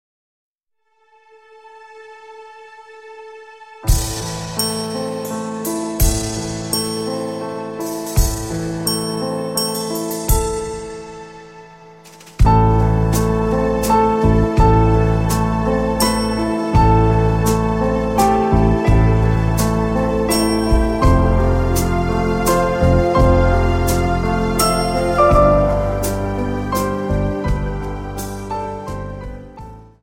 Dance: Slow Waltz 28